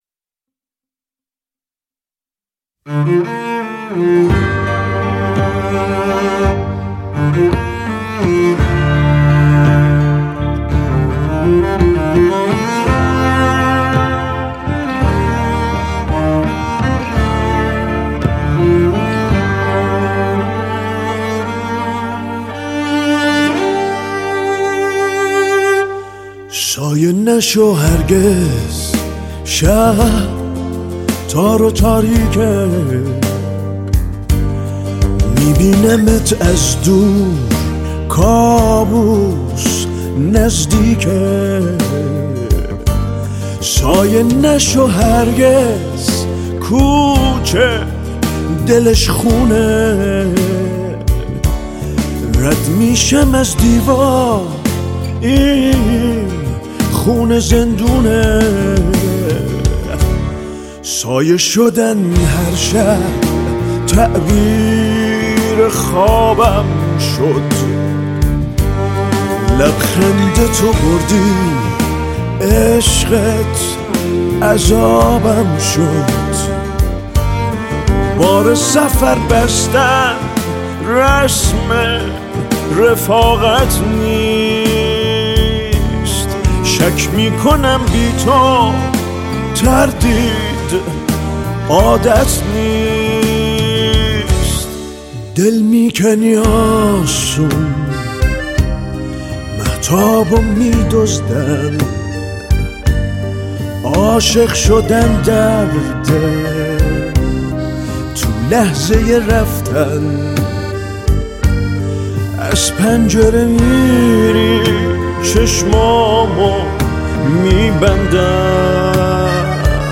• دسته آهنگ پاپ